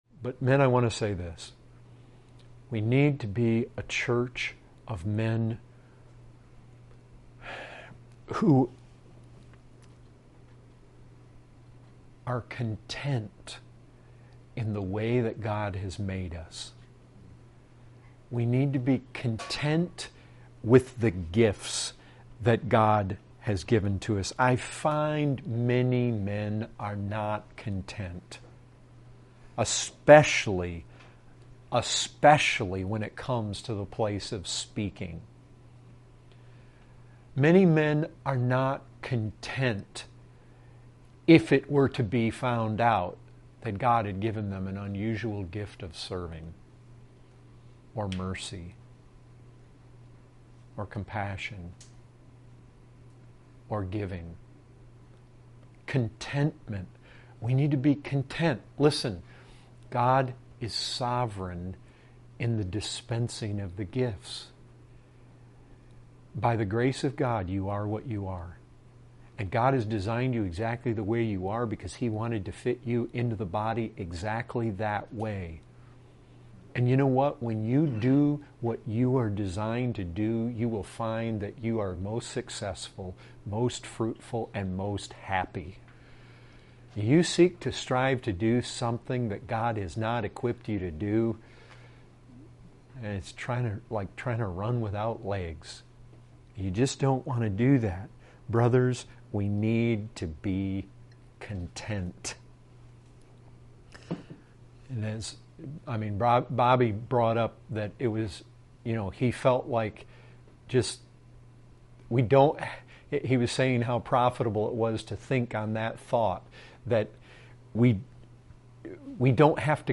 2:30 | Excerpt | We need to be content with the gifts that God has given to us.